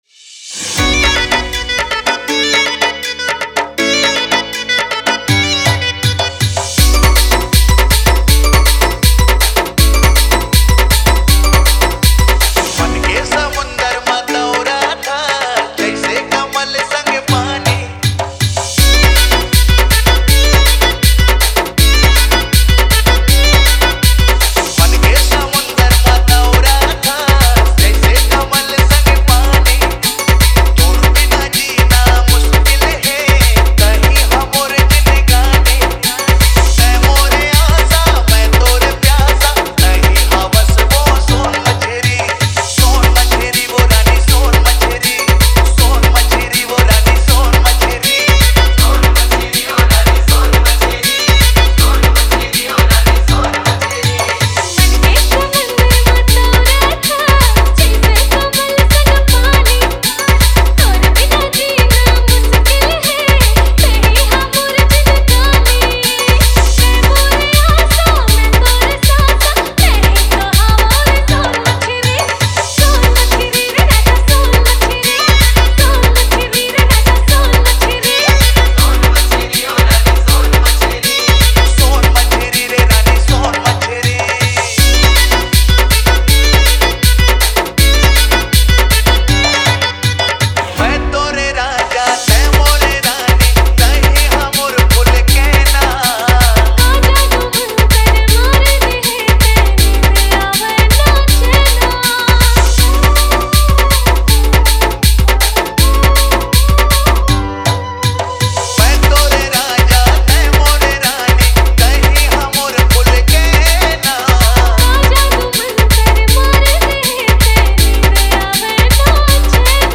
CG ROMANTIC DJ REMIX